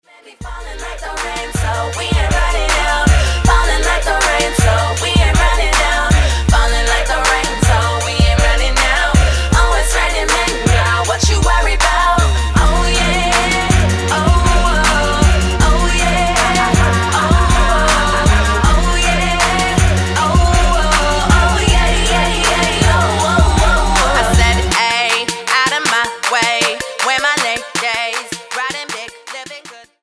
W/ Vocals